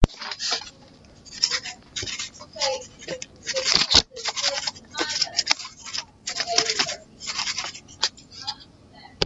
铅笔
描述：刚用过铅笔和纸，开始涂鸦/写字